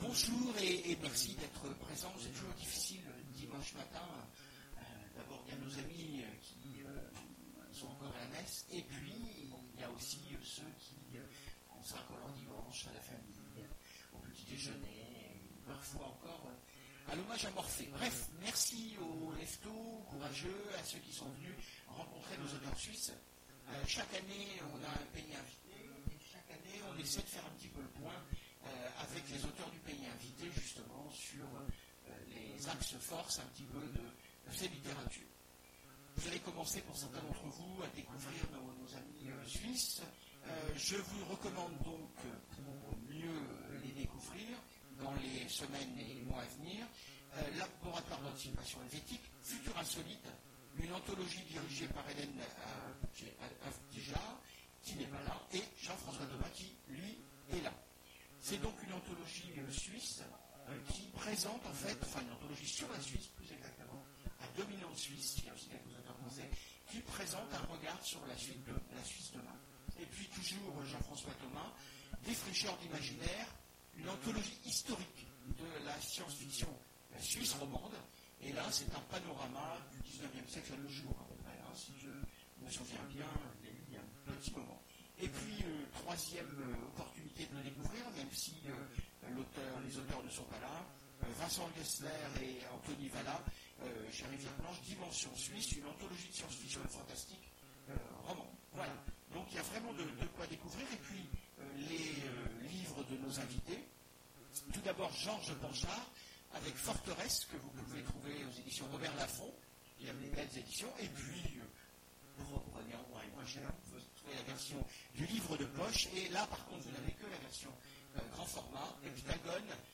Imaginales 2017 : Conférence Écrire de la SF en Suisse…